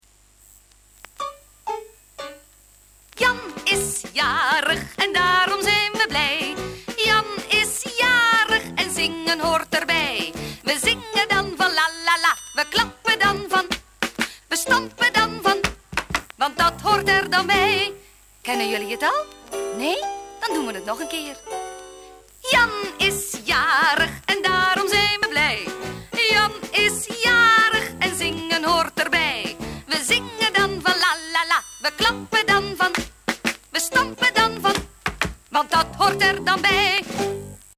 Liedjes en Verhaal